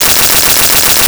Arcade Movement 01.wav